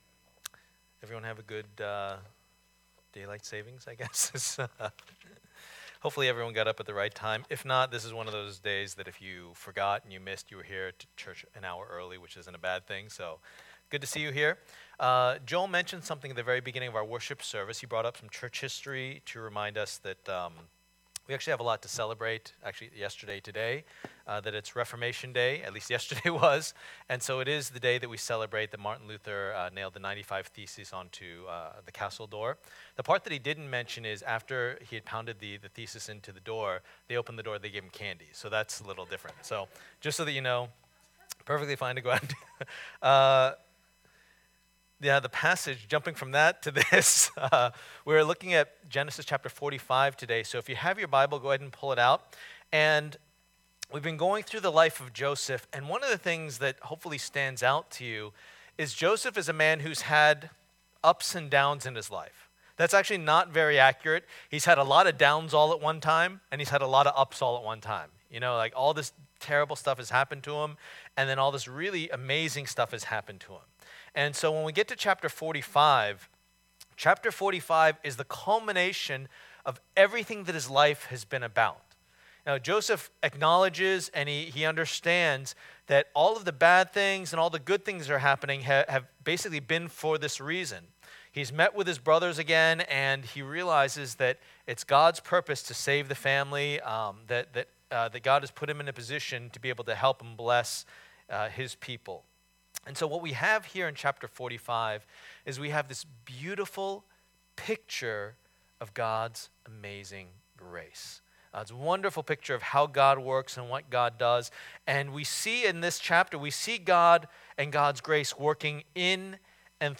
Passage: Genesis 45:1-15 Service Type: Lord's Day